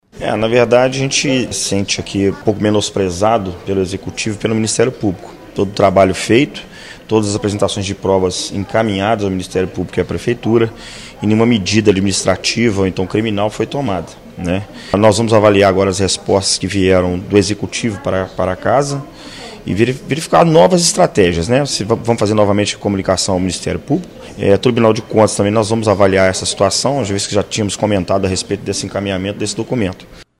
vereador Sargento Mello Casal